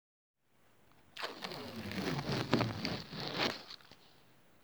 Zo’n stuk tape van zo’n kledingroller afscheuren
Ja! Dit is het geluid!